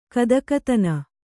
♪ kadakatana